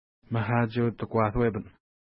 ID: 177 Longitude: -61.7439 Latitude: 55.3666 Pronunciation: ma:ha:tʃew-təkwa:tuwepən Translation: White Sucker River Fork Feature: fork in a river Explanation: Named in reference to nearby lake Makatsheu-nipi (no 176).